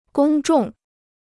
公众 (gōng zhòng): Öffentlichkeit; Publikum.